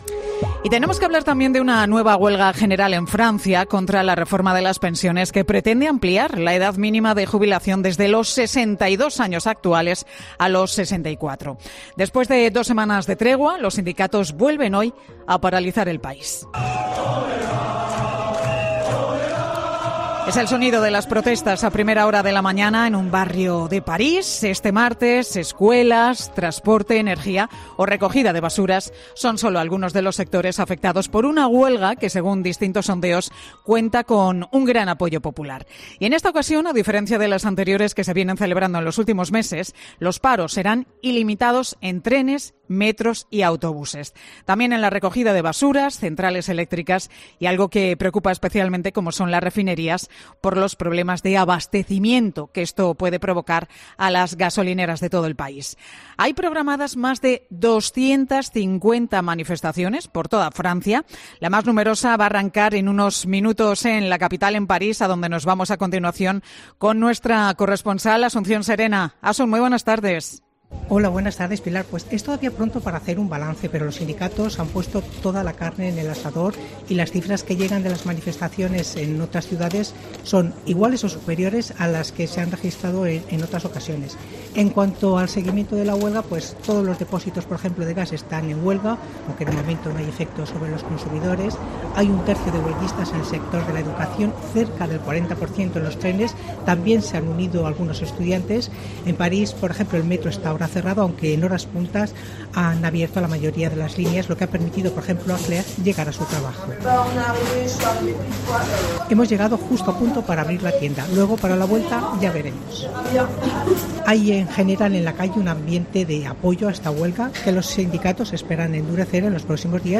corresponsal